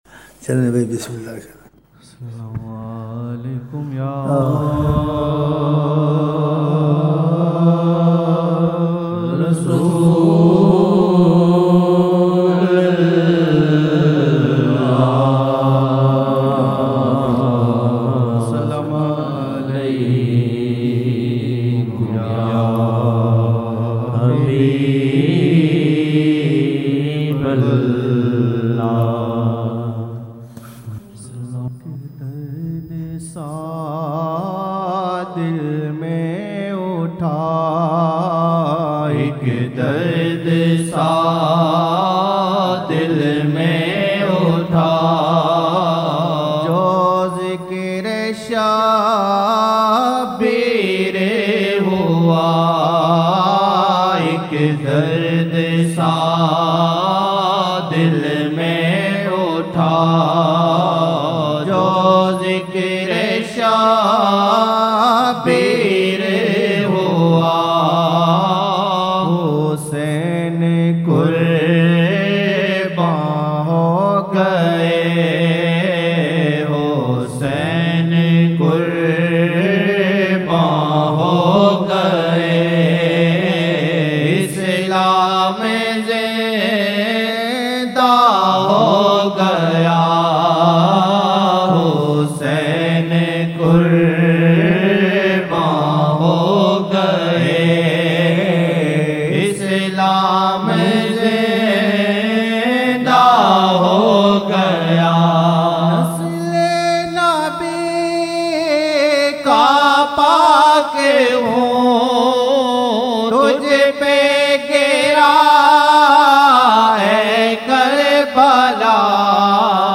Ek dard sa dil mein utha jo zikr-e-Shabbir hua, Hussain RA qurbaan ho gaye Islam zinda ho gaya 2008-01-19 19 Jan 2008 New Naat Shareef